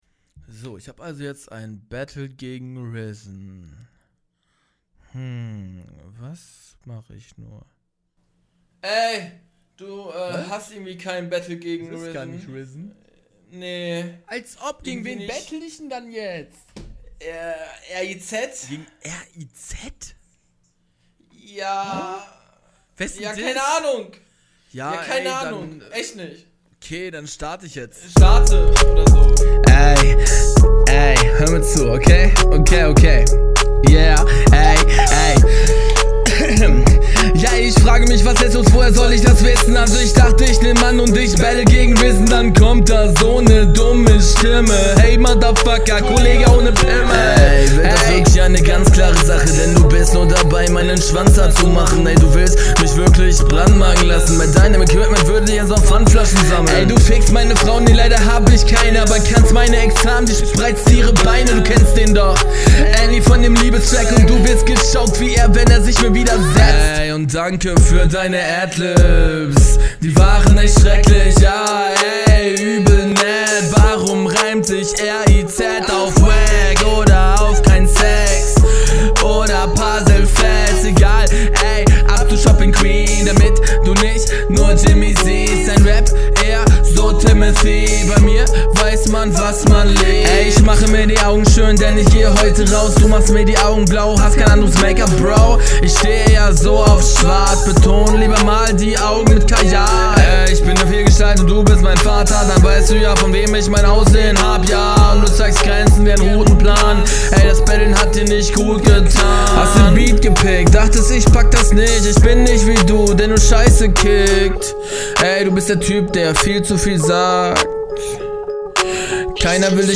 Der Sound kommt fett und wuchtig rüber, wie es in einem Battle sein sollte.